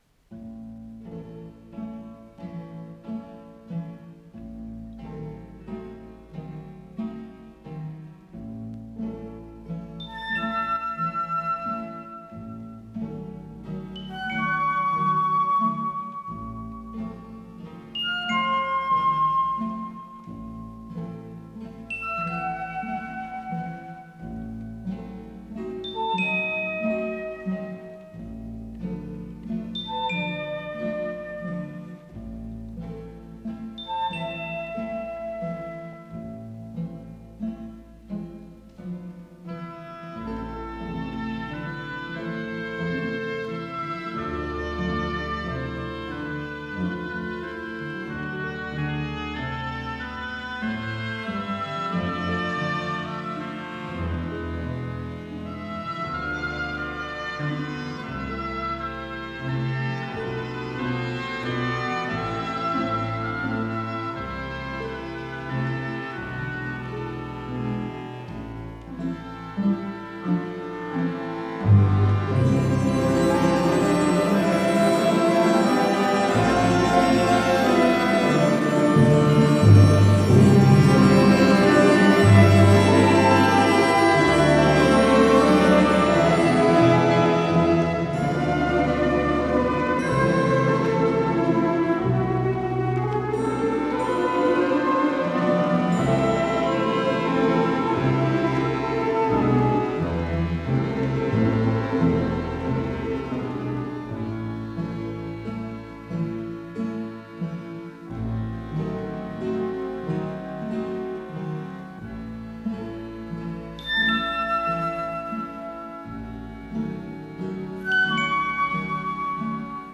с профессиональной магнитной ленты
Пролог, ля мажор
Танцы мушкетёров, соль мажор